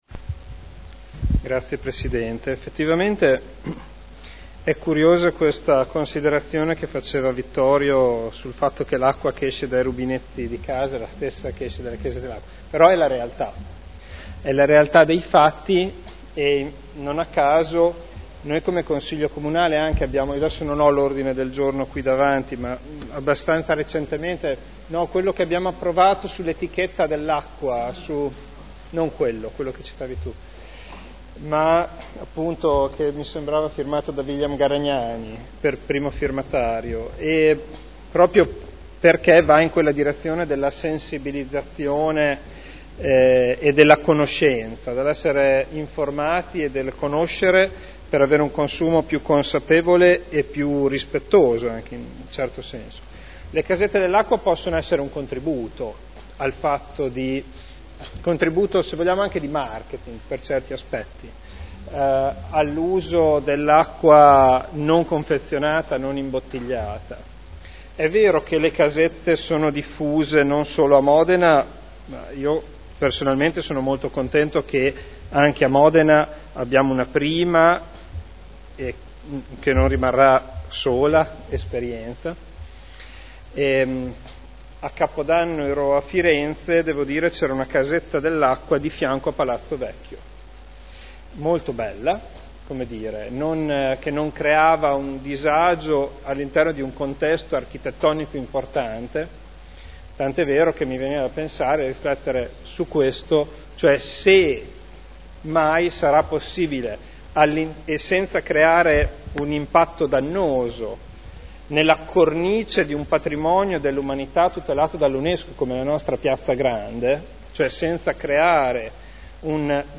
Seduta del 07/05/2012. Interrogazione dei consiglieri Sala, Cotrino, Goldoni (P.D.) e Ricci (Sinistra per Modena) avente per oggetto: “Dati utenze della “Casetta dell’acqua””. Trasformata in interpellanza su richiesta del Consigliere Ballestrazzi.